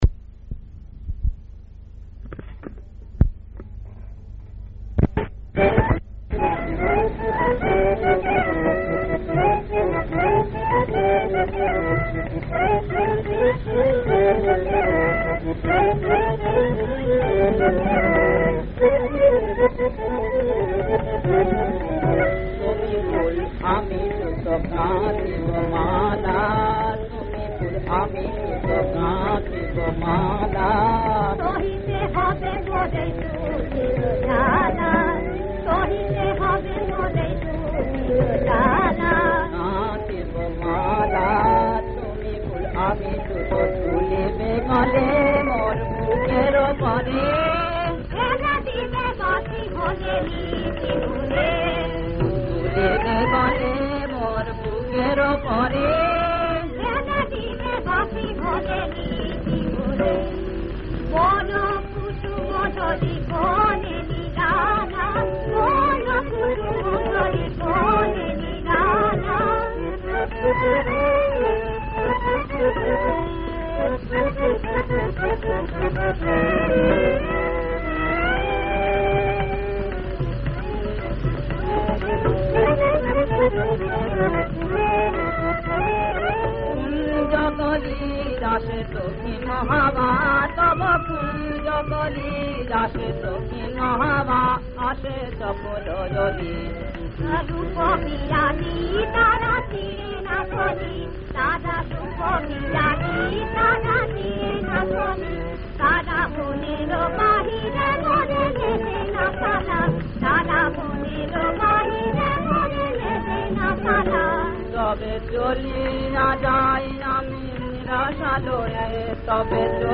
ডুয়েট গান।
• সুরাঙ্গ: স্বকীয়
• তাল: কাহারবা
• গ্রহস্বর: গা